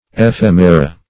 Ephemera \E*phem"e*ra\, n. [NL., fr. Gr.